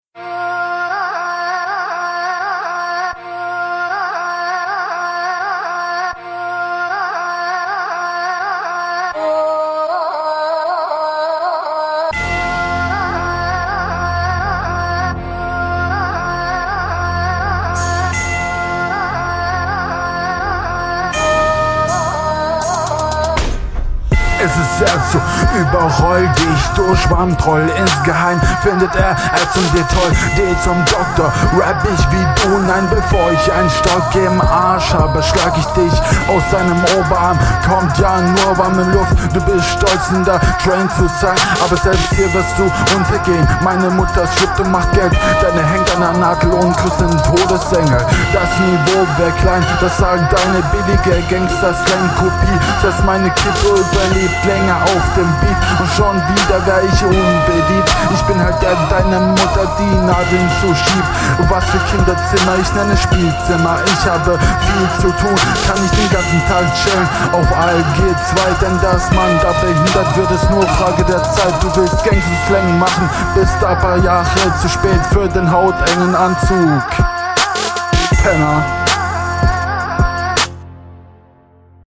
Die rückrunde Der Beat wird hier mit introlänge gespielt ohne intro gag.
Du bist flowlich noch etwas unsicher unterwegs.